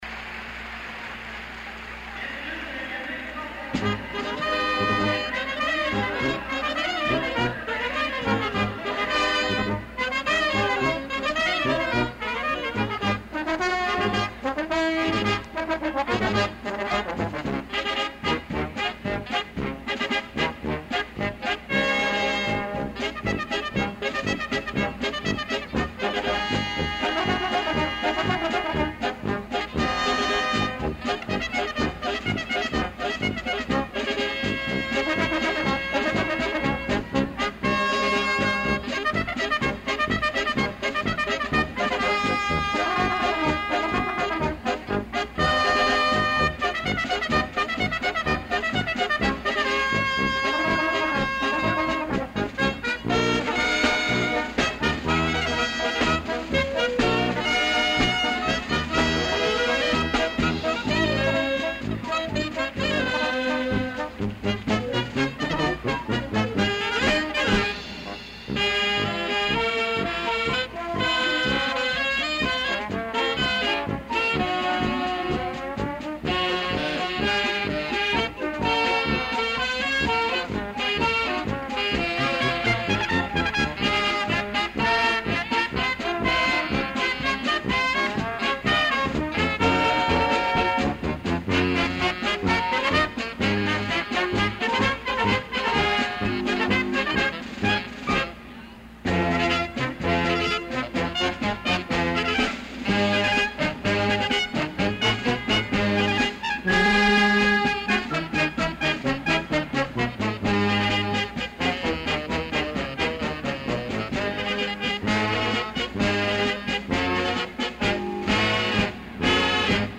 The 20th Annual Aladdin Temple Shrine Circus took place in Columbus, Ohio in April 1970 in the Ohio State Fairgrounds coliseum. The first-rate band of local musicians
air calliope